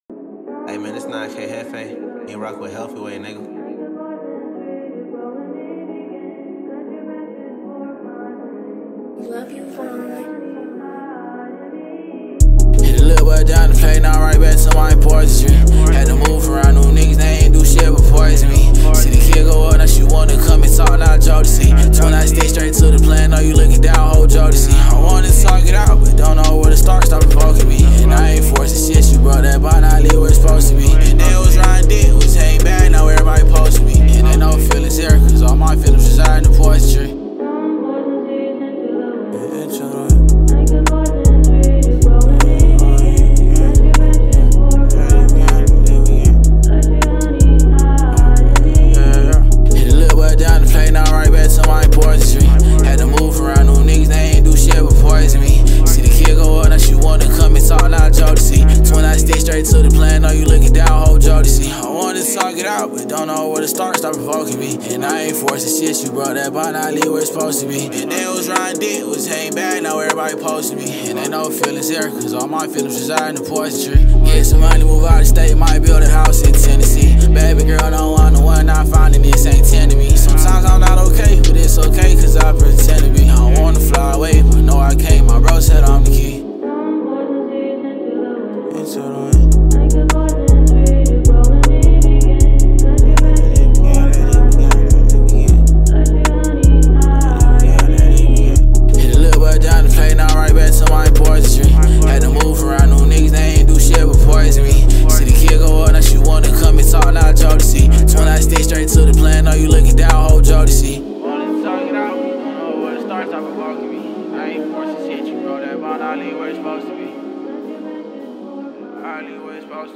начинающий рэпер